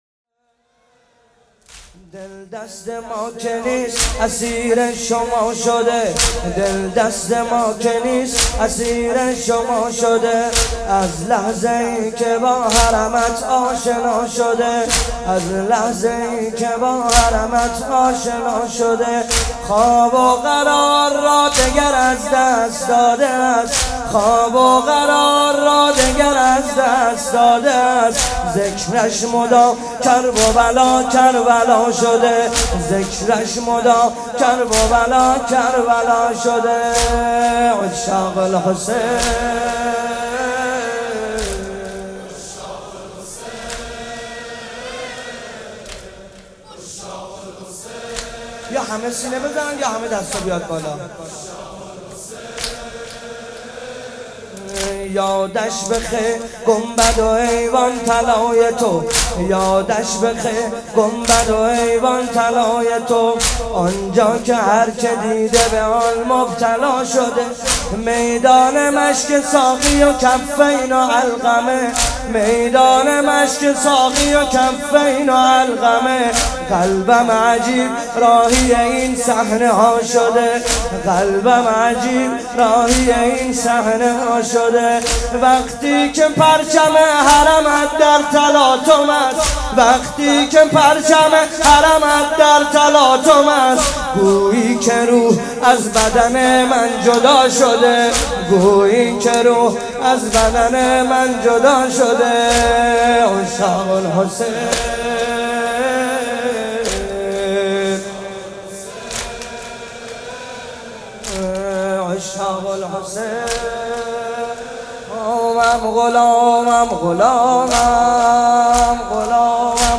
مناسبت : عاشورای حسینی